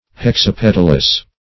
Search Result for " hexapetalous" : The Collaborative International Dictionary of English v.0.48: Hexapetalous \Hex`a*pet"al*ous\, a. [Hexa- + petal: cf. F. hexap['e]tale.]
hexapetalous.mp3